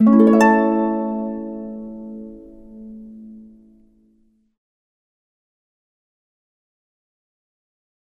Anhänge Orchestra Harp Strum 08.mp3 150 KB · Aufrufe: 325